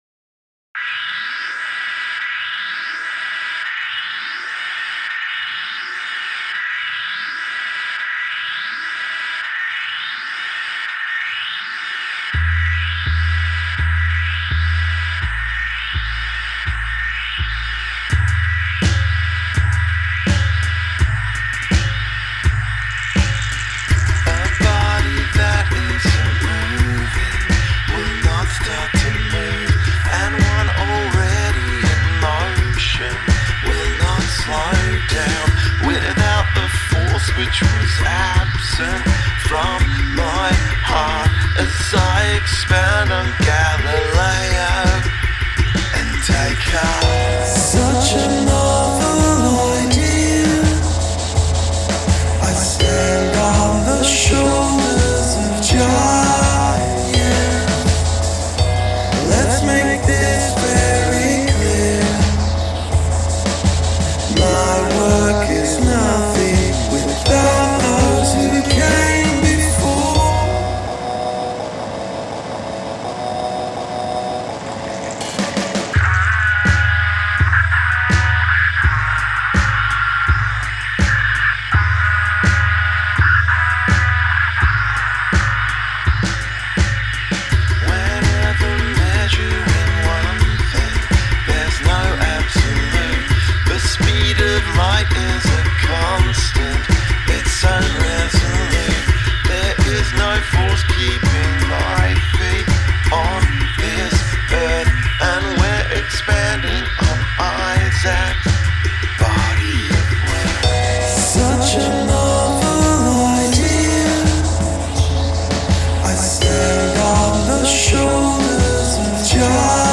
A shaken spray can slips in and out of the transitions